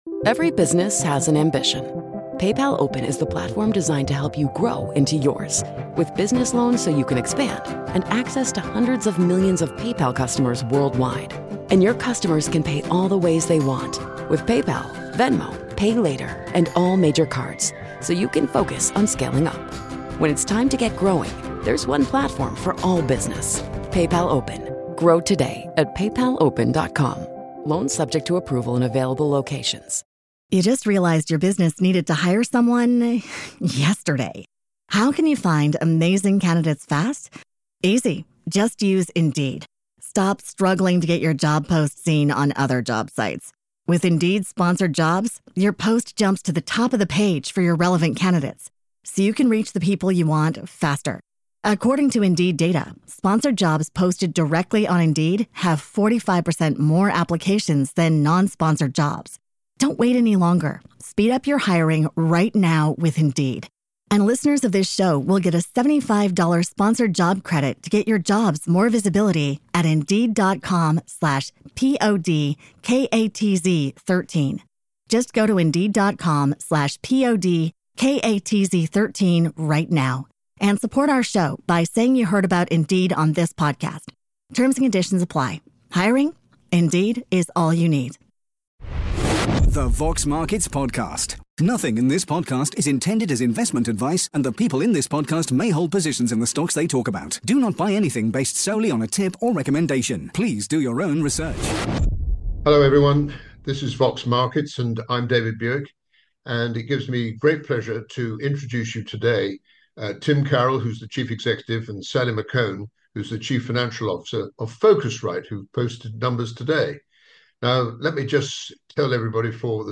Vox Markets interview